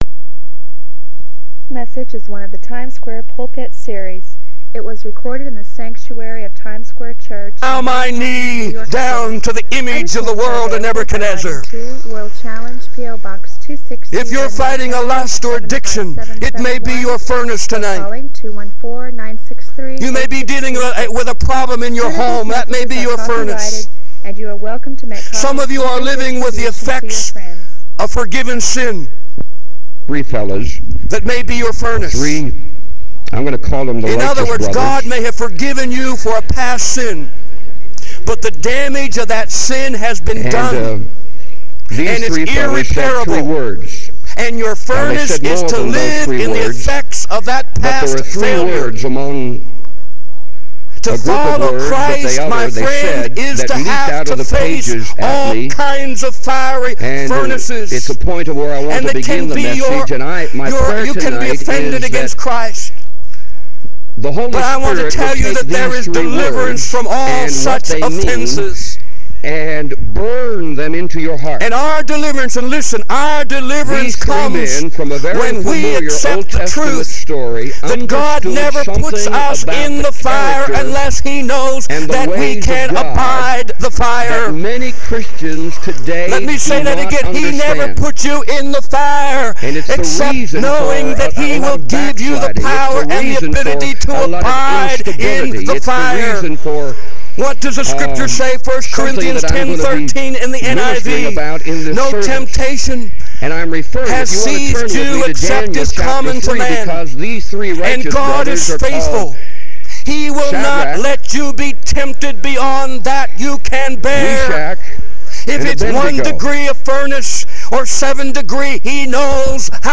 It was recorded in the sanctuary of Times Square Church.